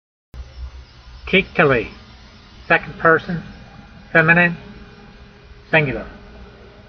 My reading (voice) in modern Israeli style is only good enough to get you started.
teek-te-lee